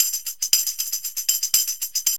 TAMB LP 116.wav